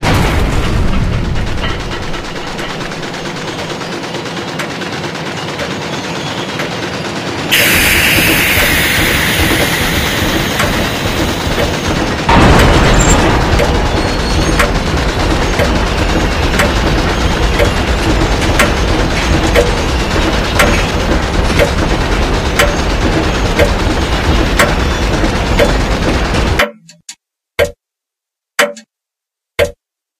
ark_activation_sequence.ogg